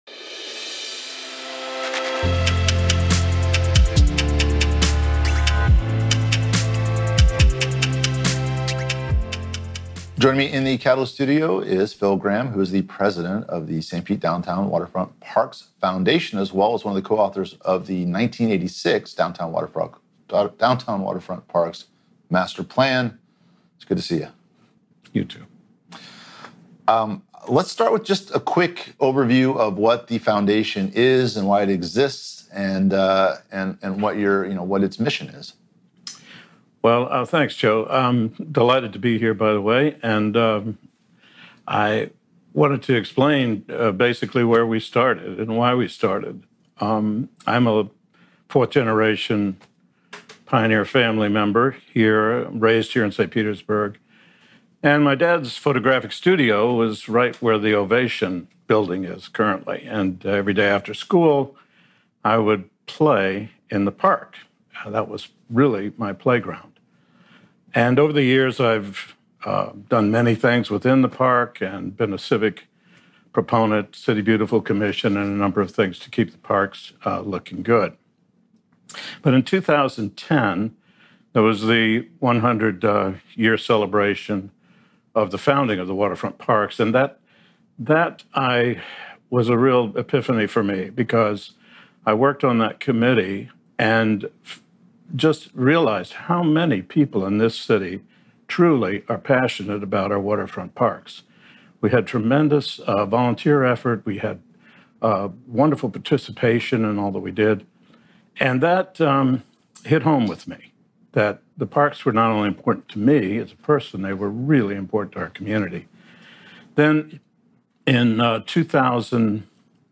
St. Pete Catalyst Interview